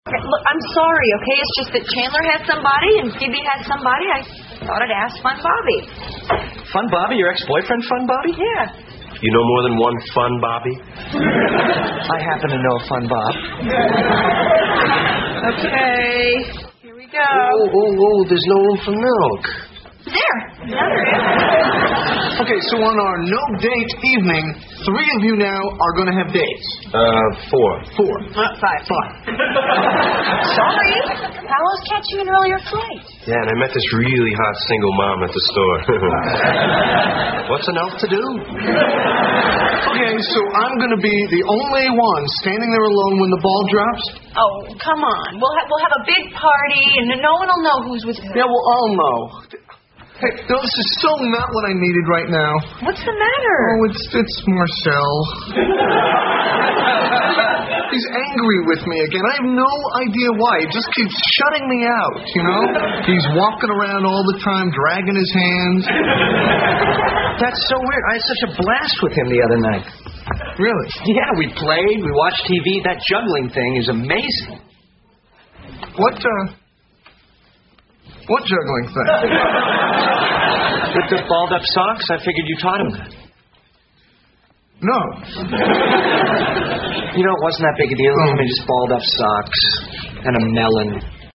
在线英语听力室老友记精校版第1季 第119期:猴子(7)的听力文件下载, 《老友记精校版》是美国乃至全世界最受欢迎的情景喜剧，一共拍摄了10季，以其幽默的对白和与现实生活的贴近吸引了无数的观众，精校版栏目搭配高音质音频与同步双语字幕，是练习提升英语听力水平，积累英语知识的好帮手。